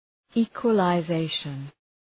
Shkrimi fonetik{,i:kwələ’zeıʃən}